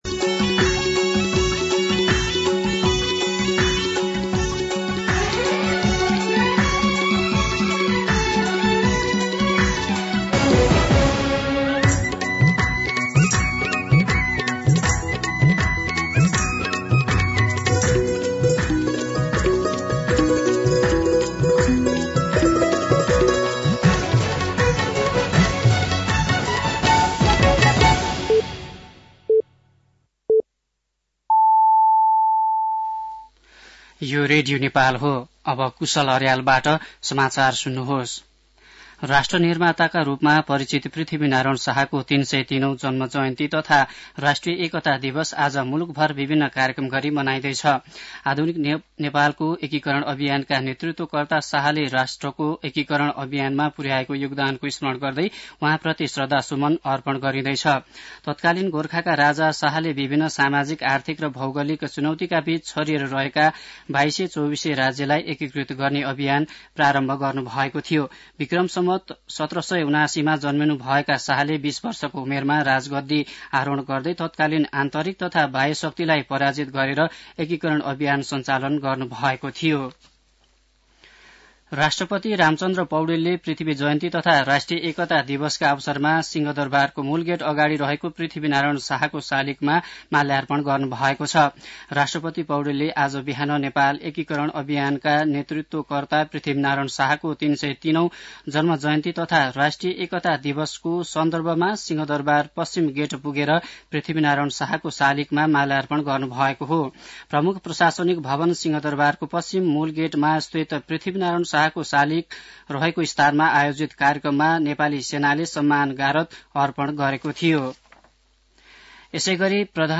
मध्यान्ह १२ बजेको नेपाली समाचार : २८ पुष , २०८१